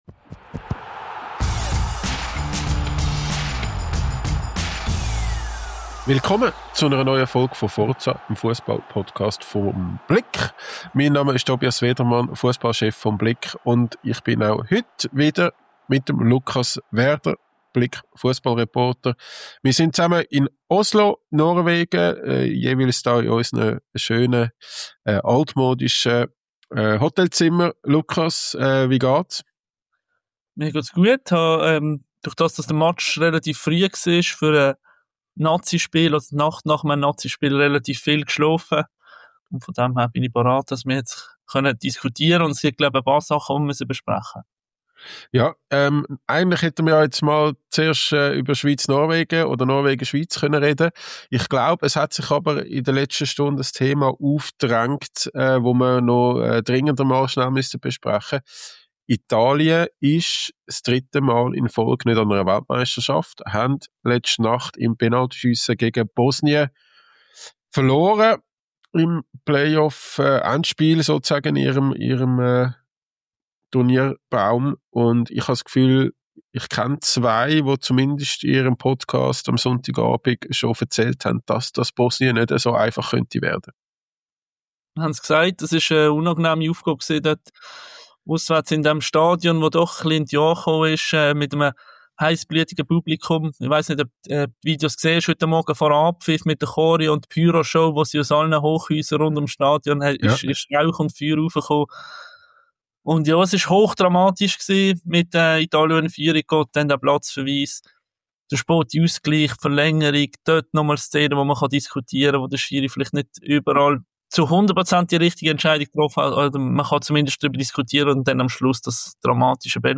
Eine Analyse
aus Oslo